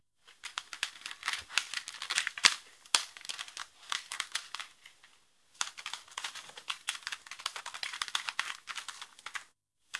Звук бумаги
Шелест страниц книги: